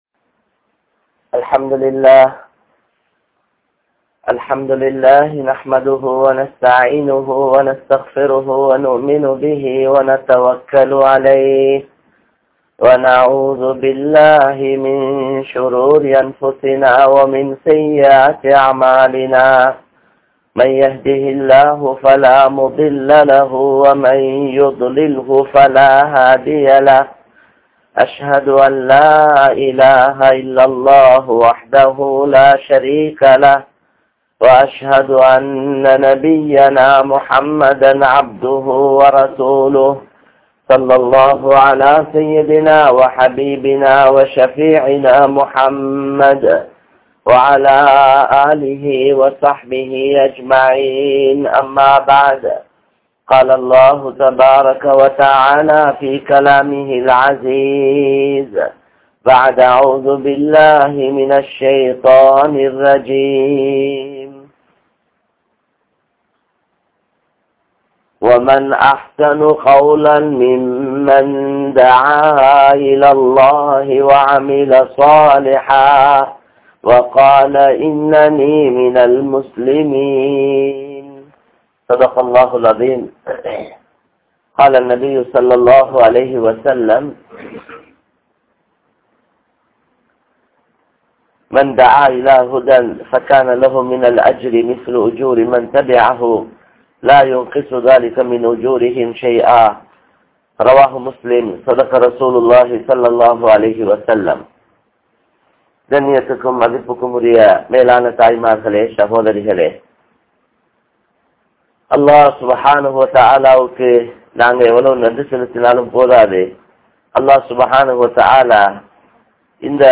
Kanneerudan Maranikkum Manitharhal (கண்ணீருடன் மரணிக்கும் மனிதர்கள்) | Audio Bayans | All Ceylon Muslim Youth Community | Addalaichenai